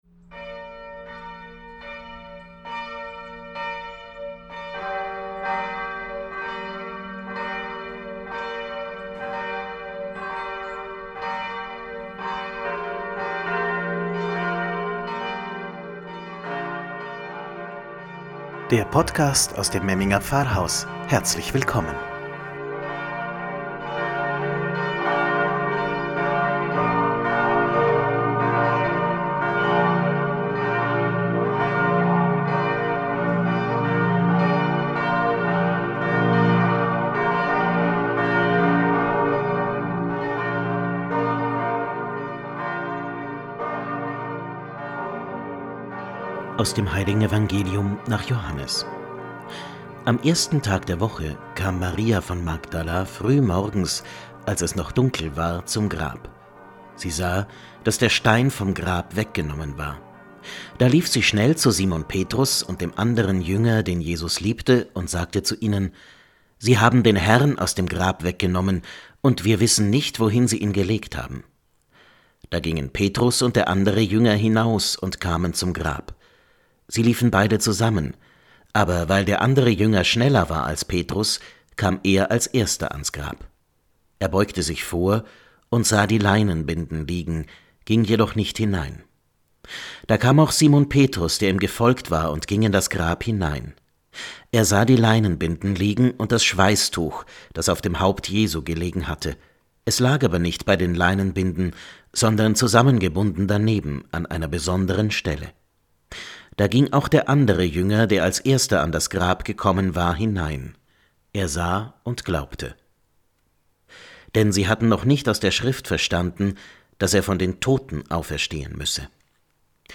„Wort zum Sonntag“ aus dem Memminger Pfarrhaus – Ostersonntag 2021